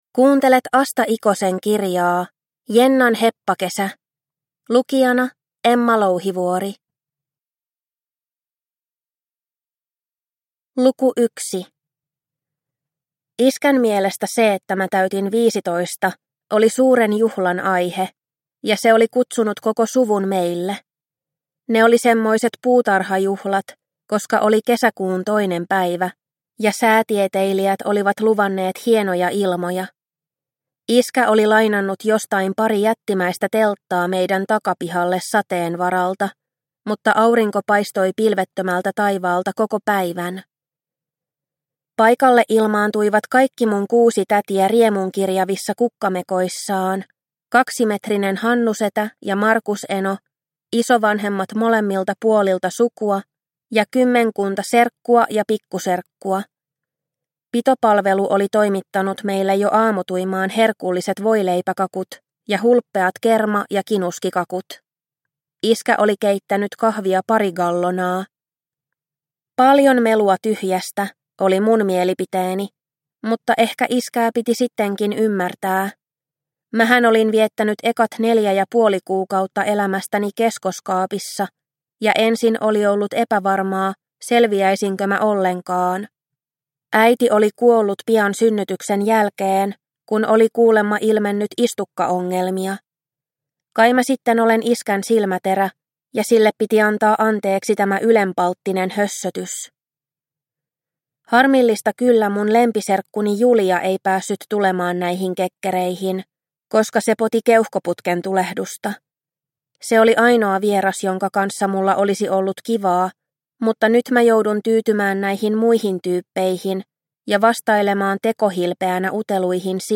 Jennan heppakesä – Ljudbok – Laddas ner